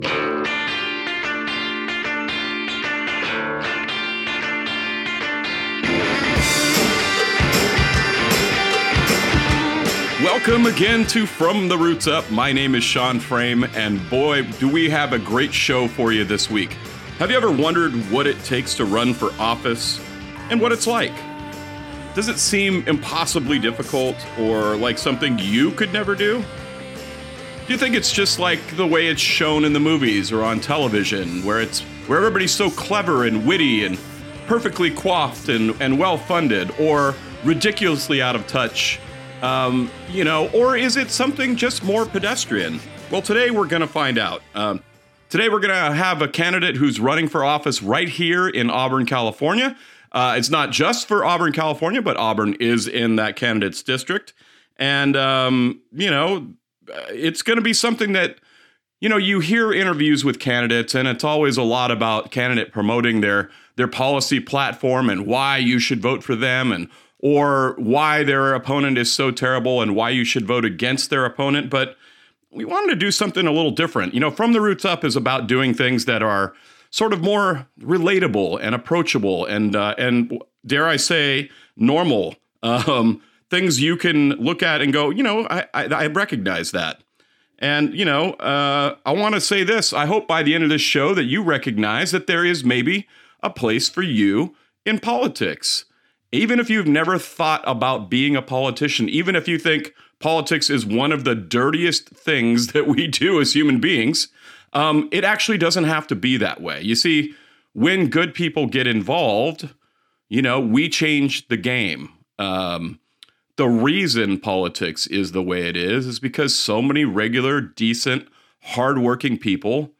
Aired August 31, 2025 on KAHI radio